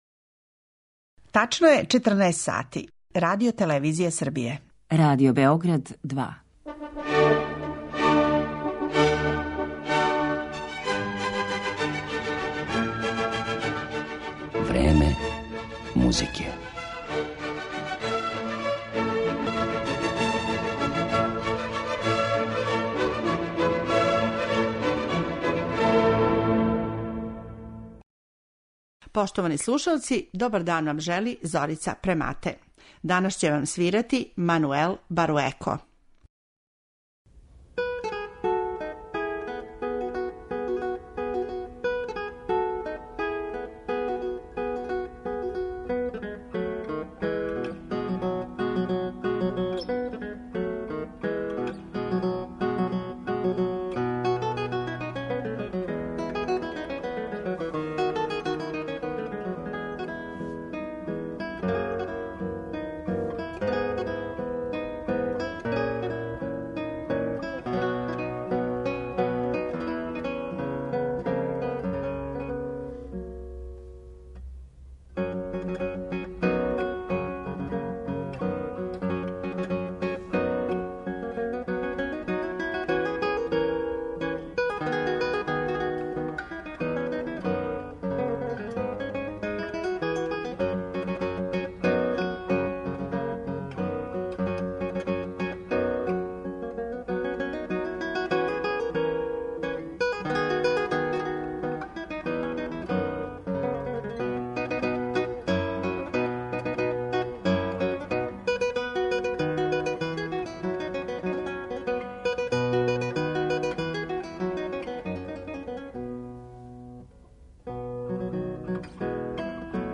америчком гитаристи кубанског порекла.